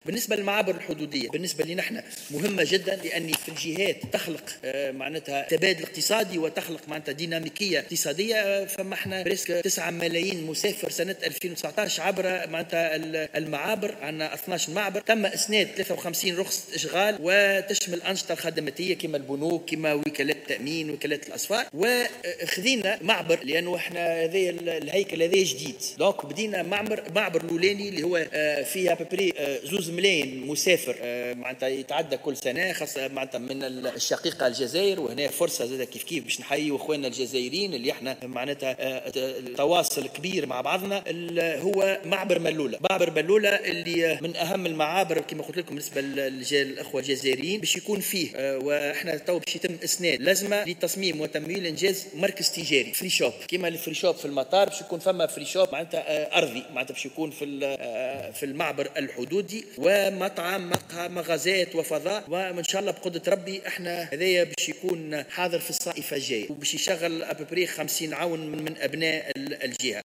وقال في رده على أسئلة النواب خلال جلسة عامة لمناقشة ميزانية وزارته، إن هذا الفضاء سيمكّن من تشغيل 50 عونا.